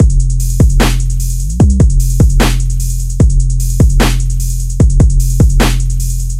敲锣打鼓
标签： 150 bpm Trap Loops Drum Loops 2.15 MB wav Key : B
声道立体声